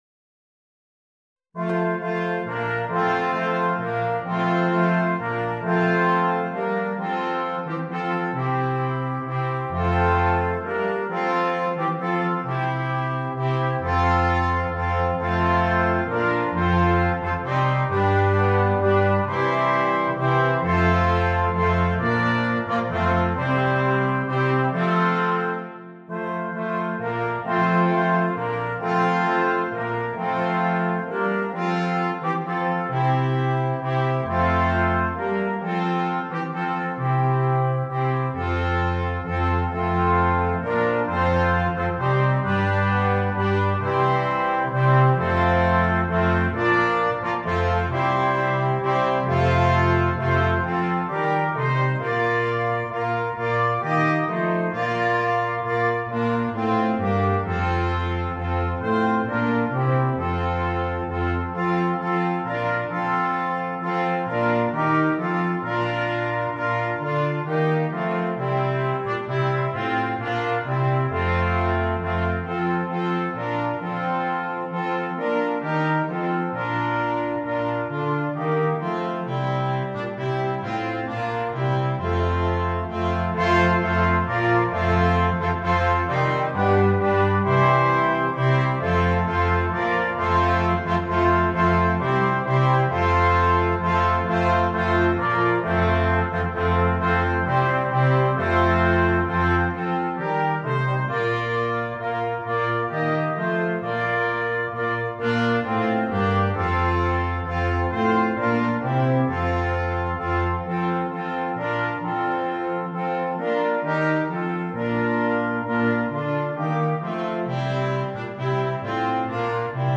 Voicing: 4 Trumpets, 3 Trombones and Tuba